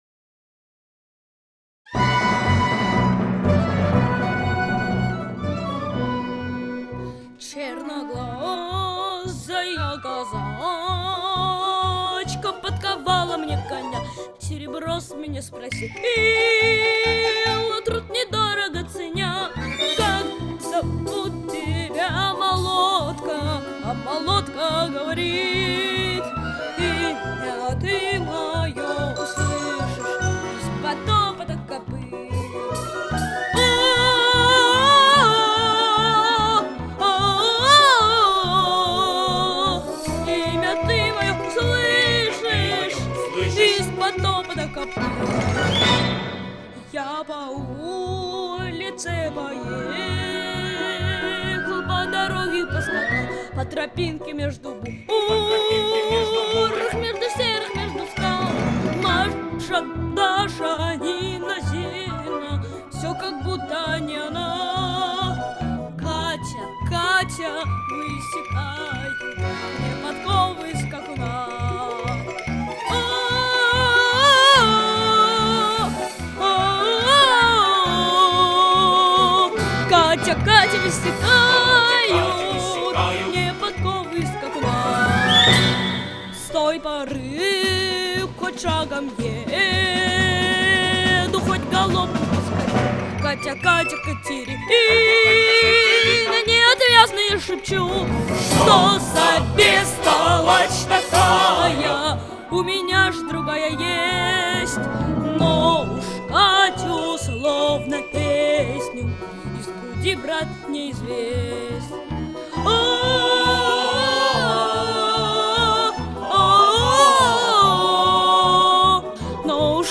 P.S. Правда, с оговоркой, что это концертный микс, поэтому по-максимуму сухой P.P.S. все щипковые - исключительно мои оркестровые) Ну и альтуха только сольная